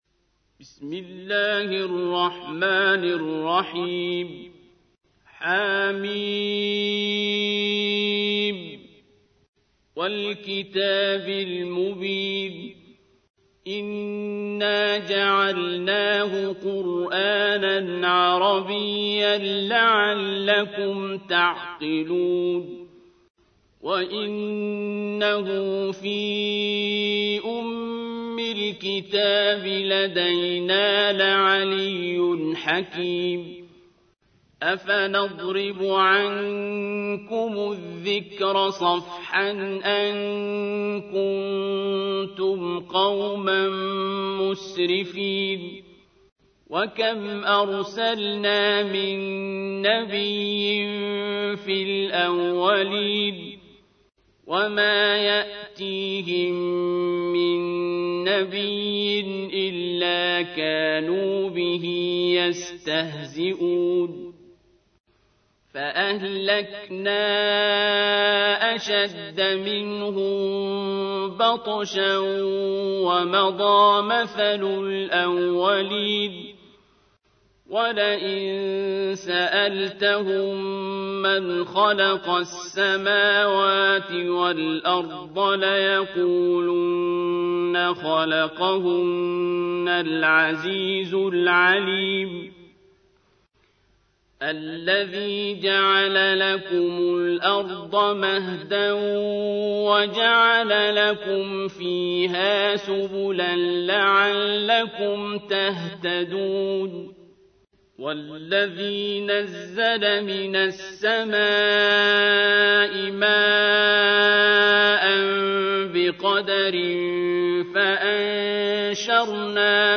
دانلود ترتیل قرآن کریم عبدالباسط + پخش آنلاین | بخش سوم